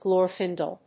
グロールフィンデル